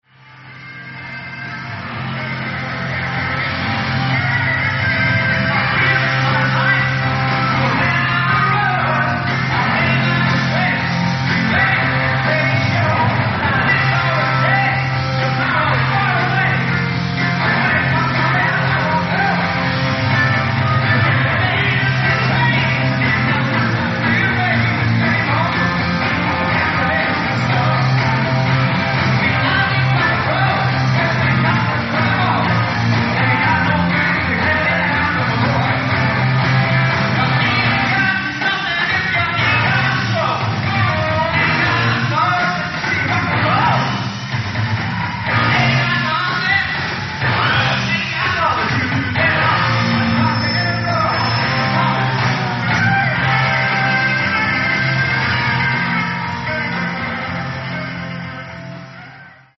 Detroit, Michigan 1977
Grade:  A-
Sound:  Remastered
Source:  Audience Recording
Lead Vocals
Guitars
Bass
Keyboards
Drums